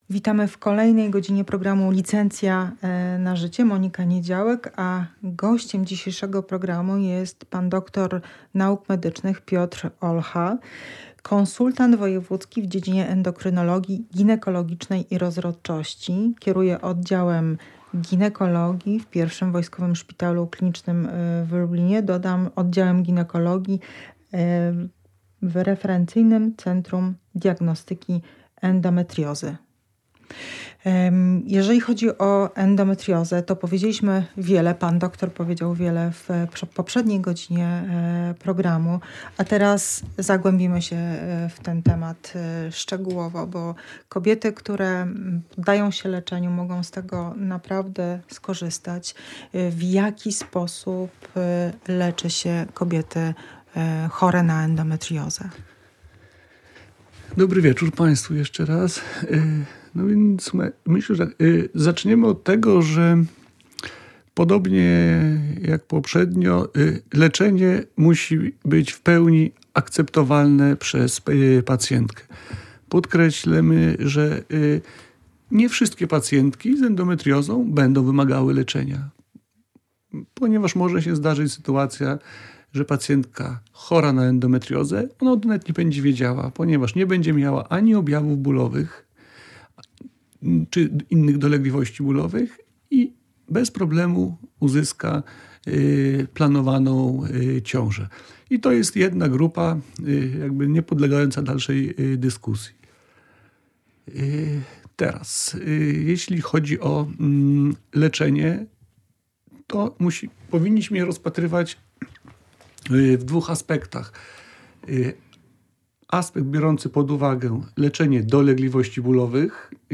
W „Licencji na życie” tym razem porozmawiamy z ginekologiem